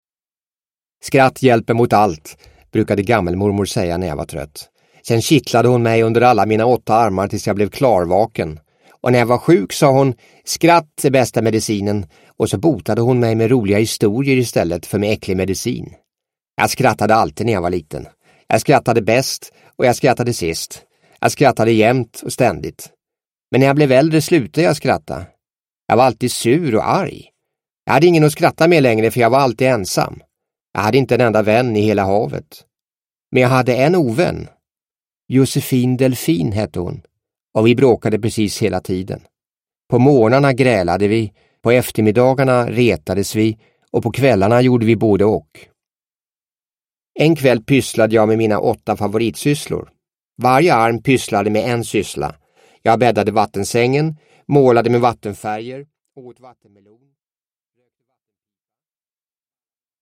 Benny Bläckfisk – Ljudbok – Laddas ner